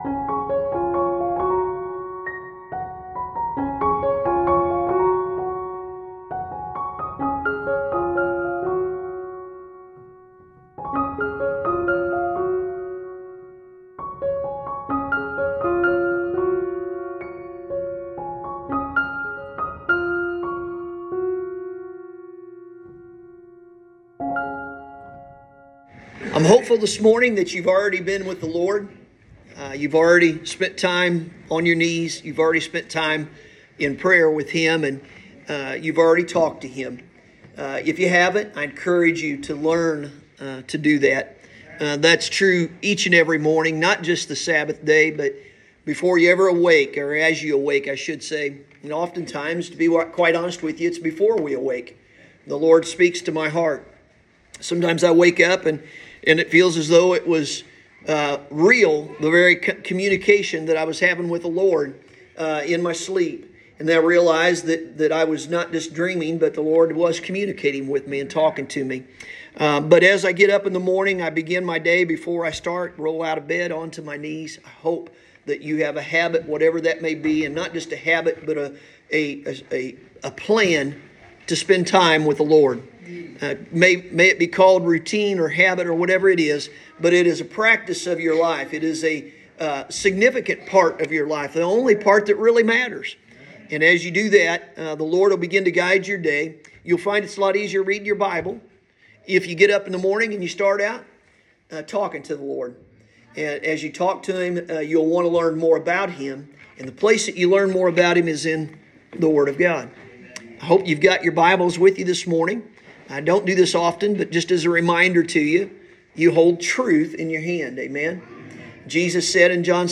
Sunday Morning – March 26, 2023